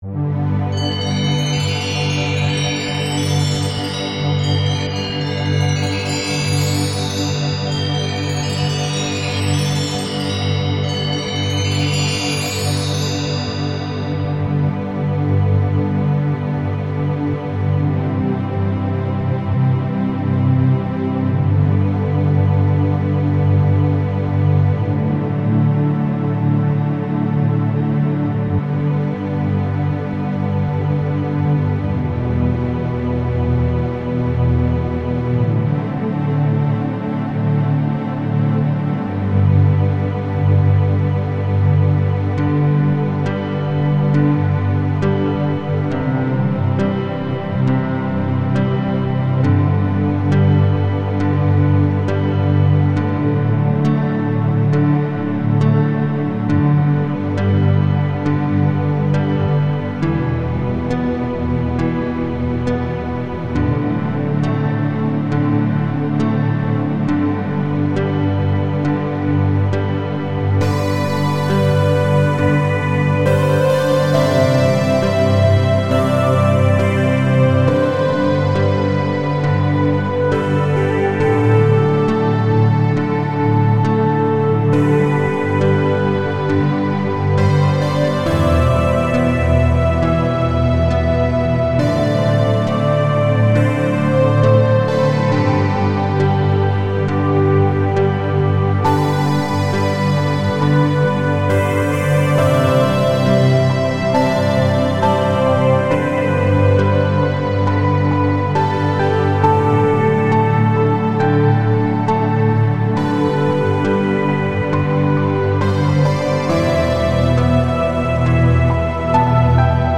An emotional piece titled When the Lights Go Down